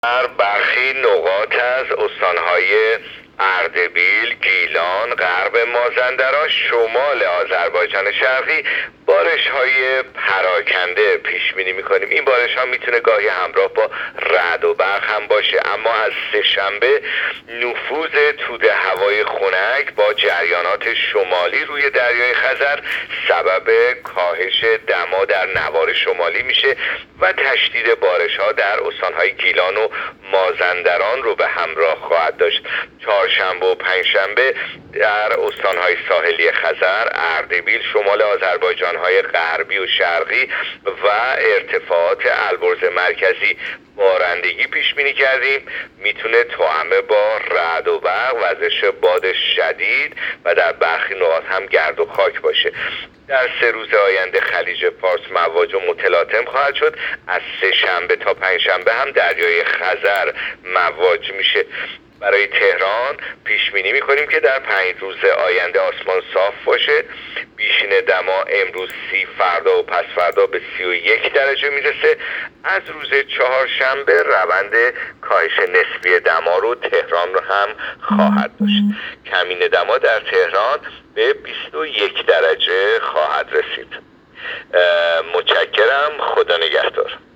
گزارش رادیو اینترنتی پایگاه‌ خبری از آخرین وضعیت آب‌وهوای ۶ مهر؛